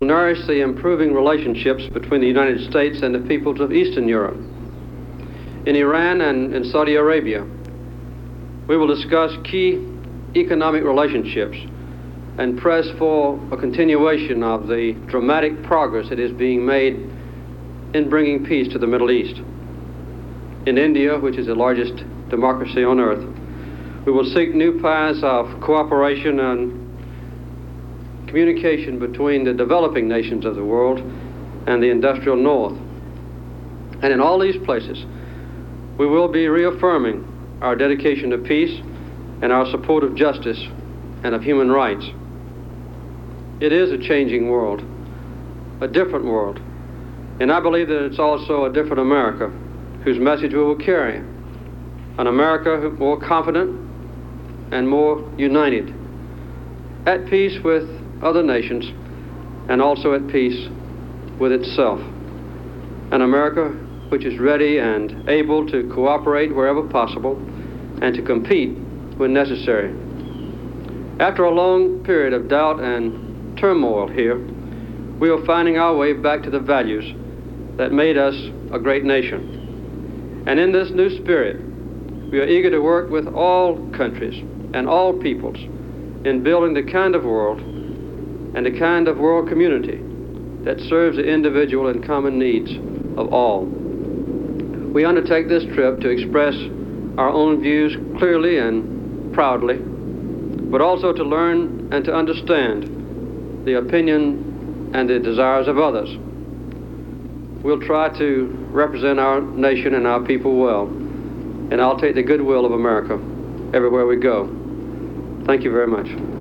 Airport statement on departure for a six-nation overseas fact finding tour
Broadcast on CBS-TV, December 29, 1977.